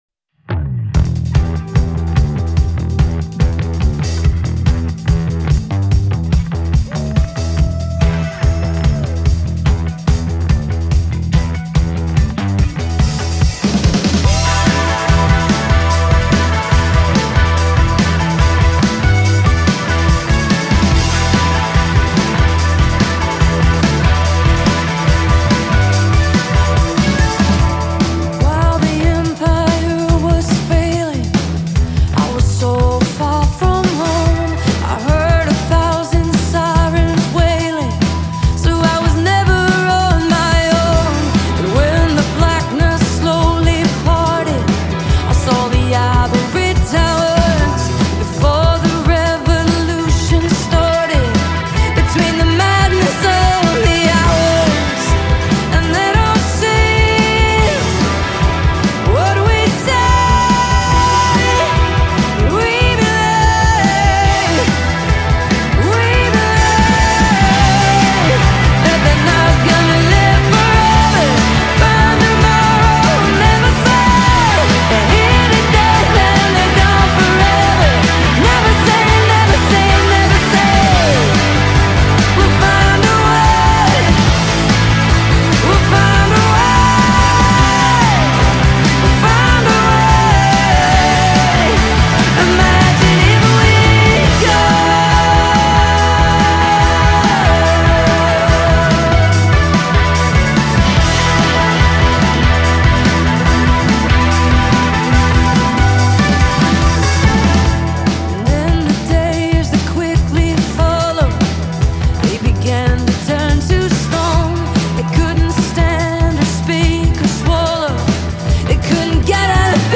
a vocal that feels both intimate and unshakable
Not because it is loud.